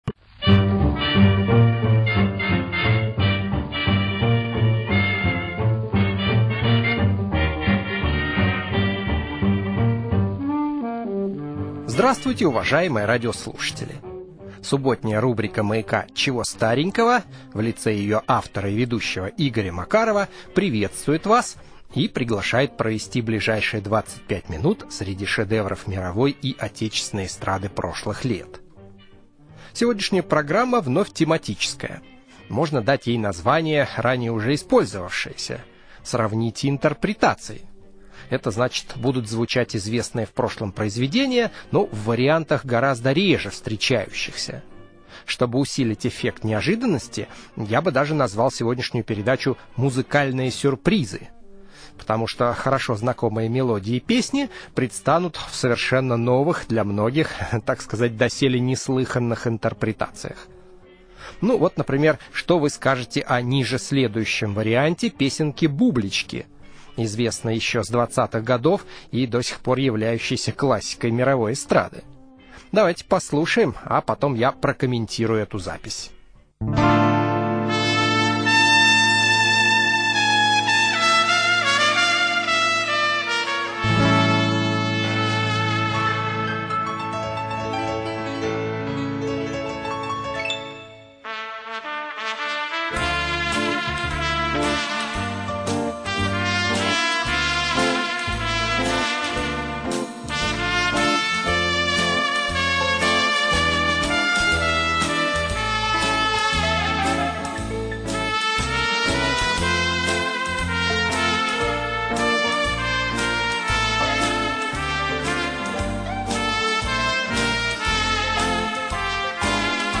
Музыкальная передача - Чего старенького 10 Музыкальная передача - Чего старенького 10